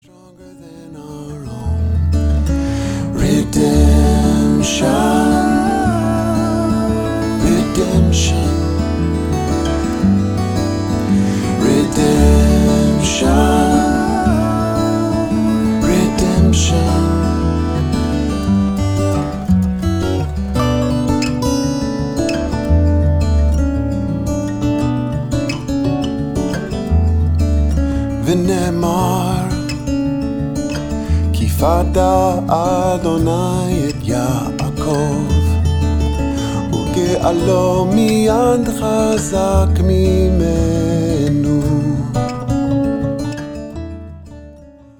This all acoustic album
vocals and violin